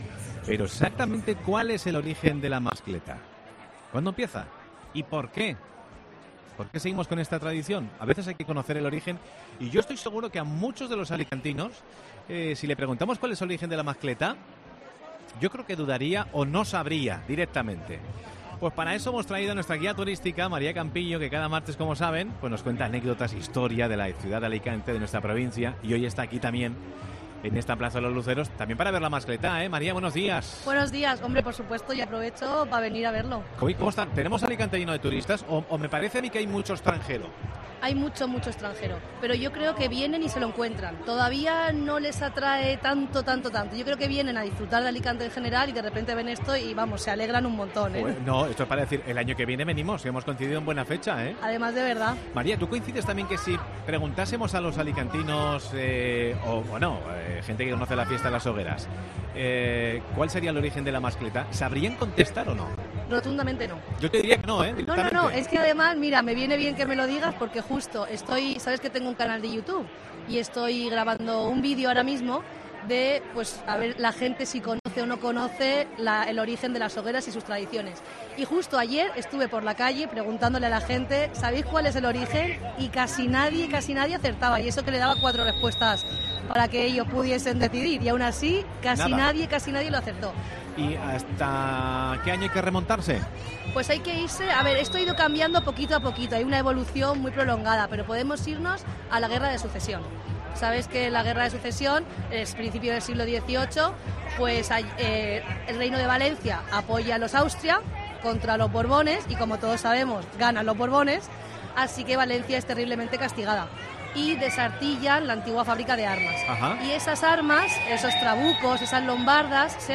en el especial Mediodía COPE desde Luceros